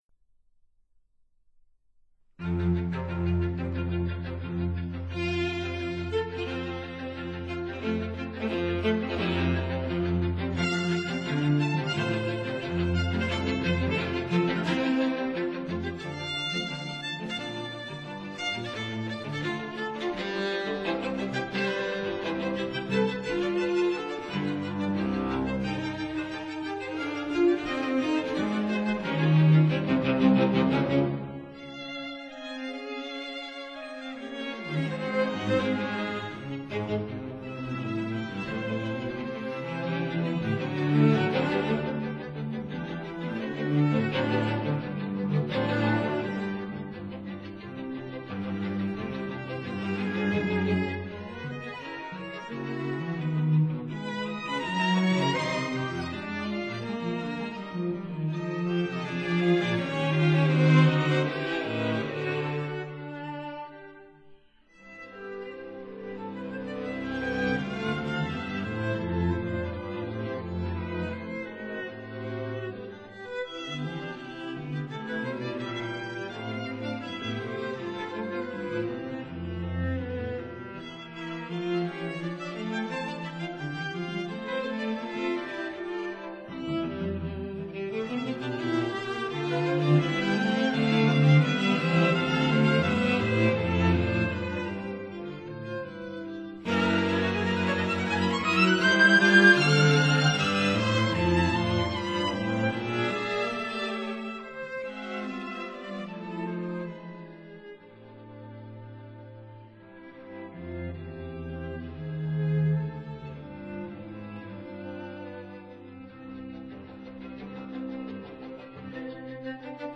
violin I
violin II
viola
cello